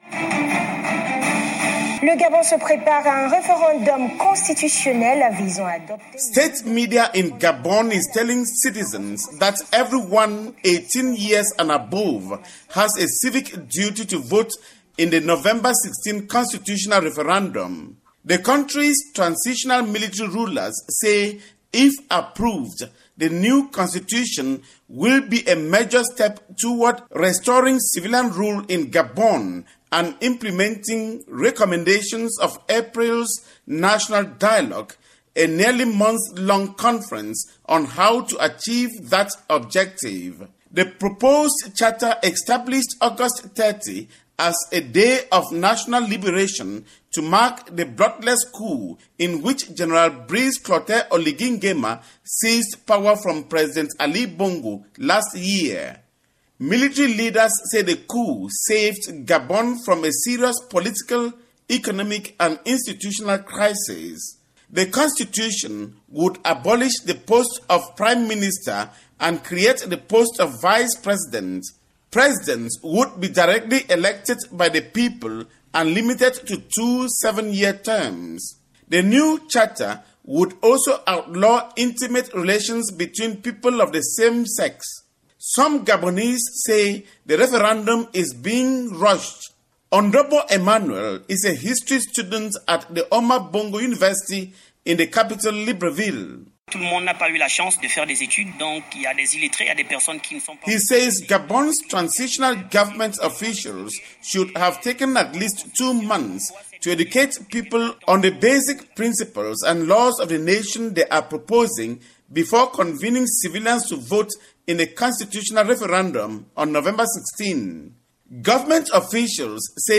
reports from neighboring Cameroon